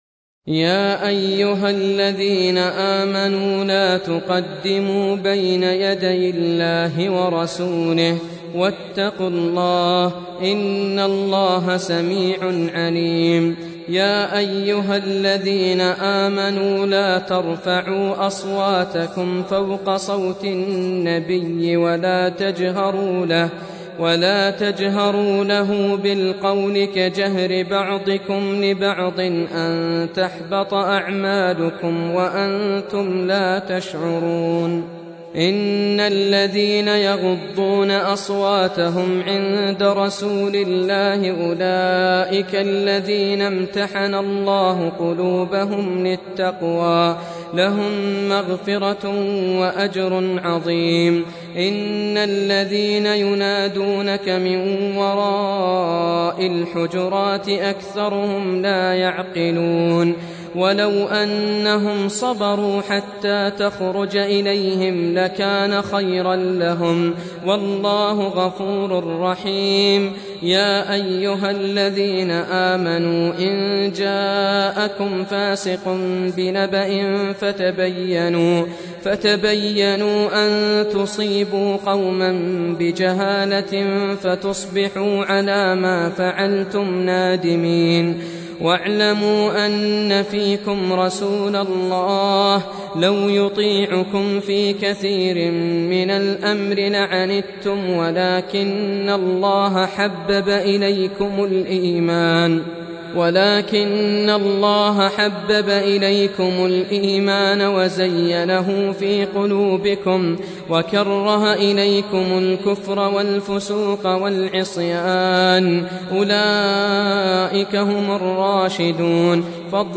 Audio Quran Tarteel Recitation
Surah Sequence تتابع السورة Download Surah حمّل السورة Reciting Murattalah Audio for 49. Surah Al-Hujur�t سورة الحجرات N.B *Surah Includes Al-Basmalah Reciters Sequents تتابع التلاوات Reciters Repeats تكرار التلاوات